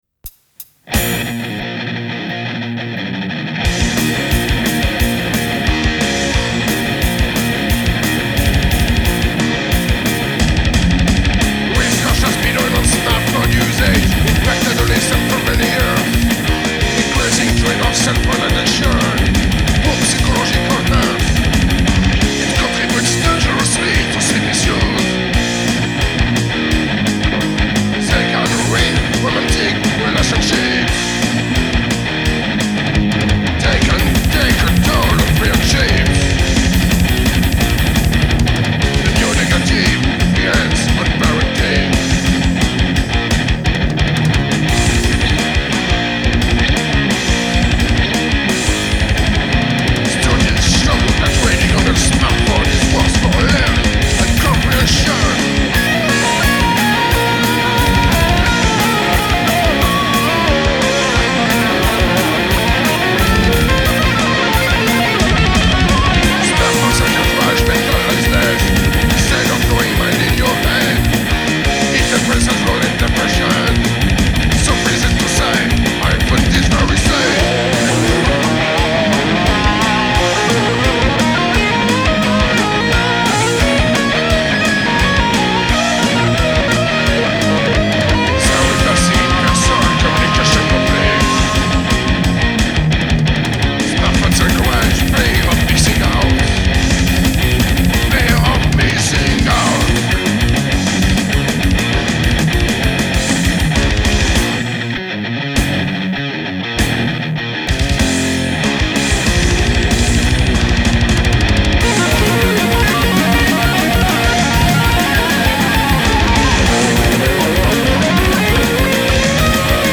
---  THRASH-METAL GAULOIS - UN PROJET NÉ À SAMAROBRIVA ---